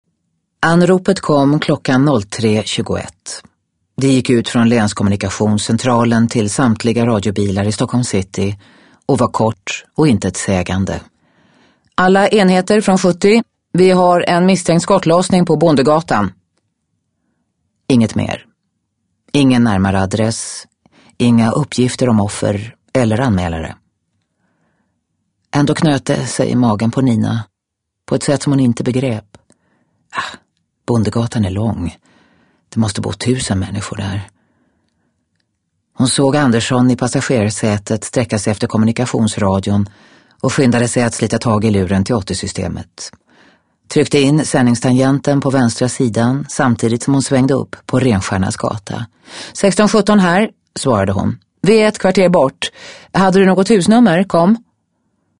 Livstid (ljudbok) av Liza Marklund